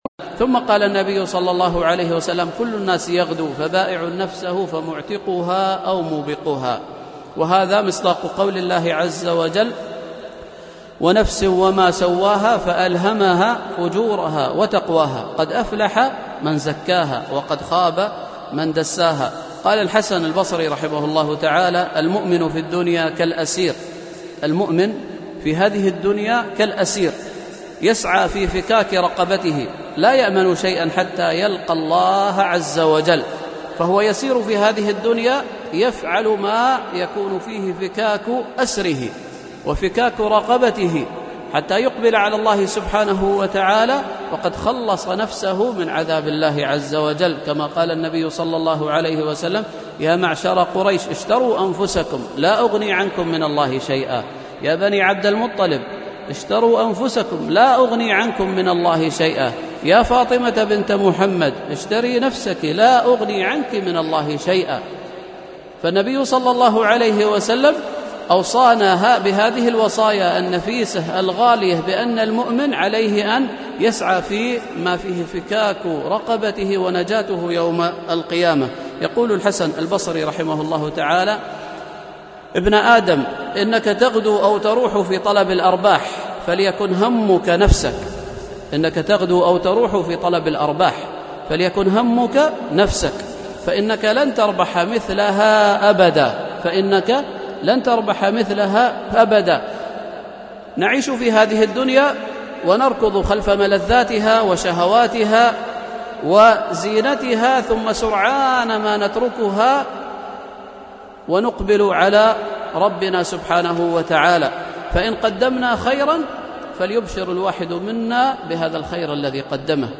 MP3 Mono 22kHz 64Kbps (CBR)